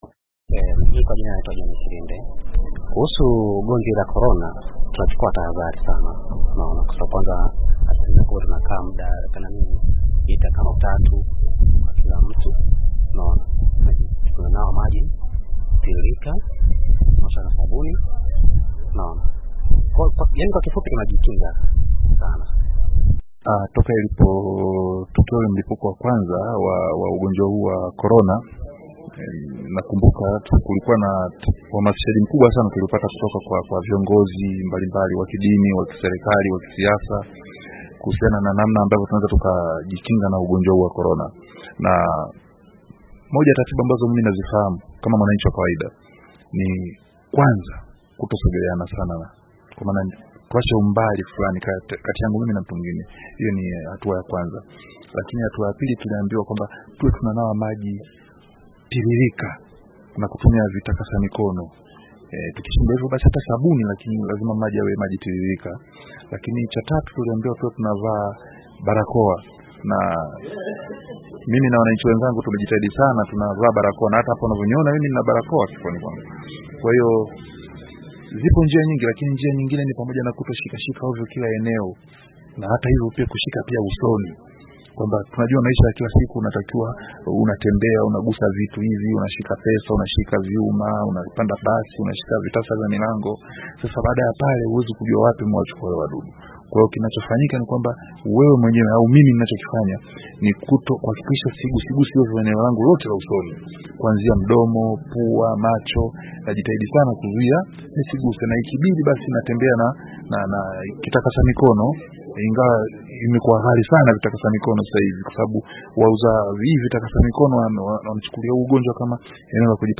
COVID-19 : MAONI YA WANANCHI